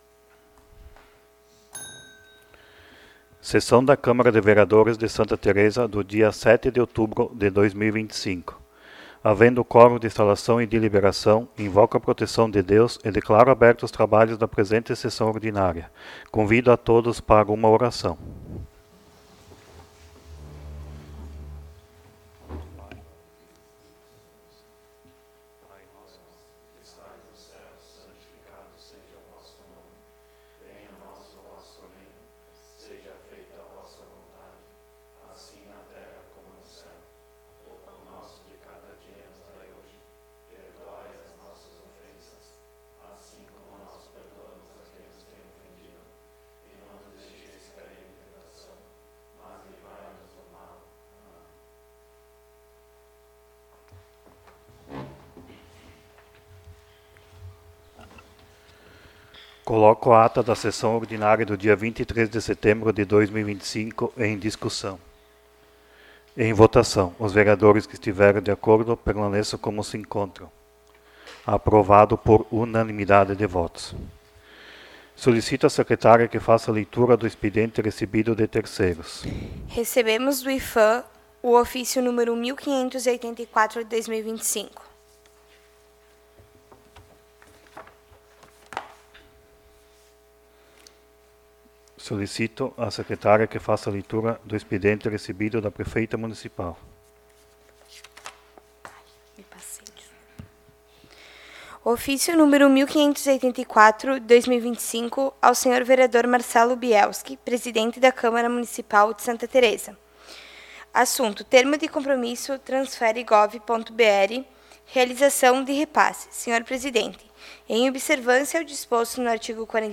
17° Sessão Ordinária de 2025